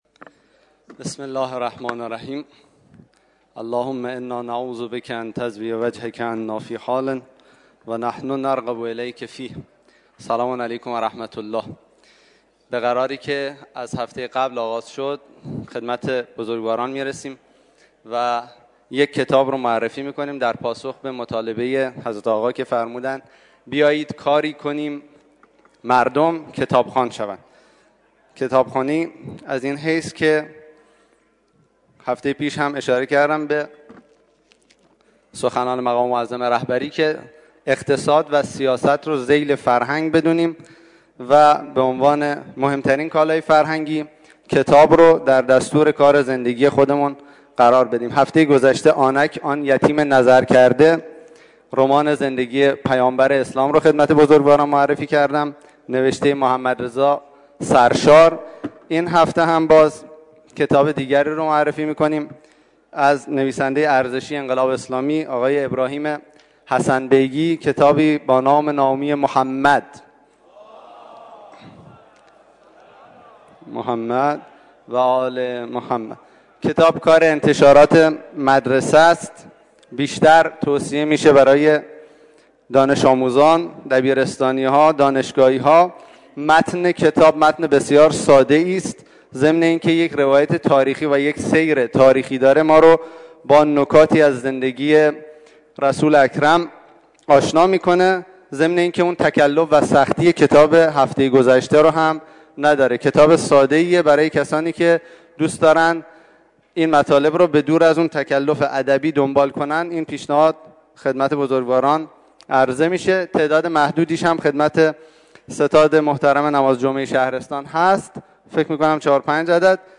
معرفی کتاب در تریبون نماز جمعه آبادان